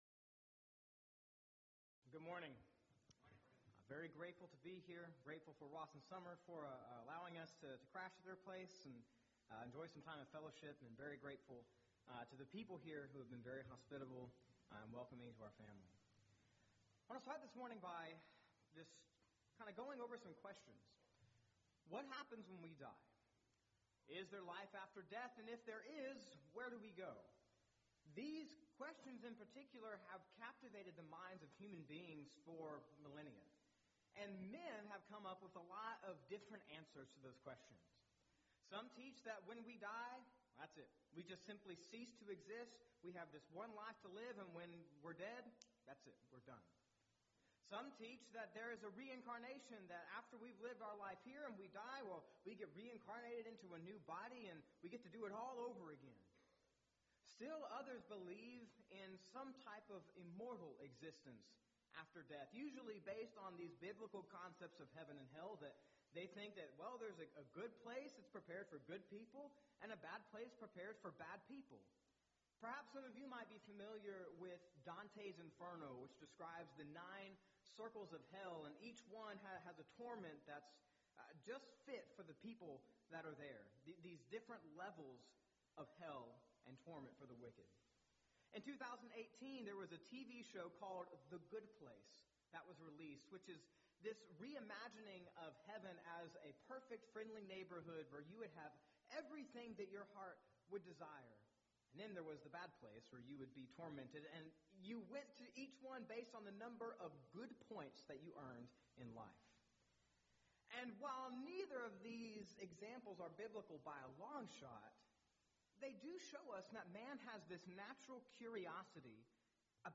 Event: 3rd Annual Colleyville Lectures